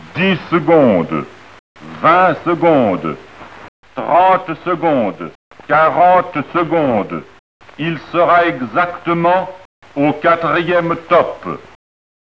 Accessible en composant un numéro dédié, elle énonce l’heure avec une précision à la seconde près, rythmée par un signal sonore.